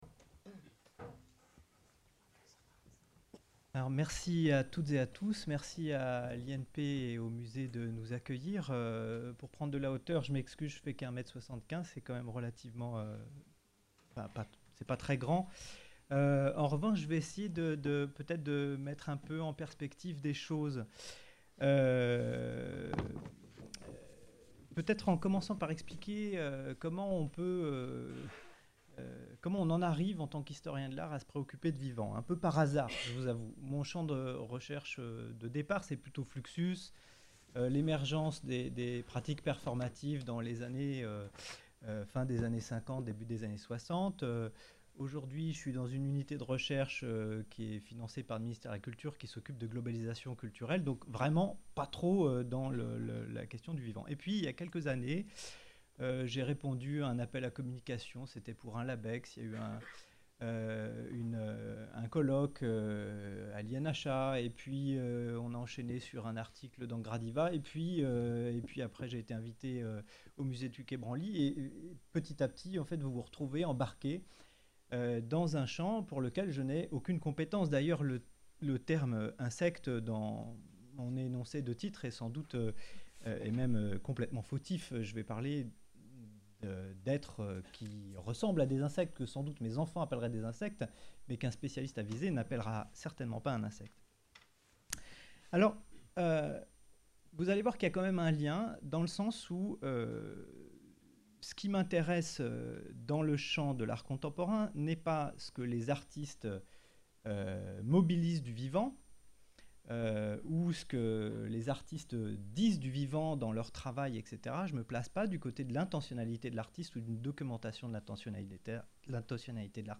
A propos de la journée d'étude du 16 avril 2019 organisée pour la première fois par l'association des élèves-conservateurs de l’Institut national du patrimoine Huit communications et une table ronde ont permis de débattre sur la place de l’animal vivant dans les institutions patrimoniales aujourd’hui.